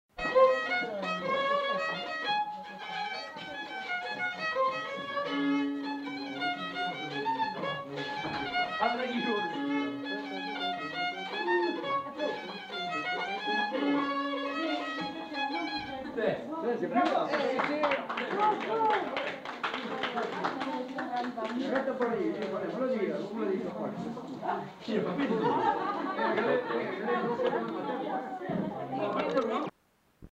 Aire culturelle : Lugues
Lieu : Allons
Genre : morceau instrumental
Instrument de musique : violon
Danse : rondeau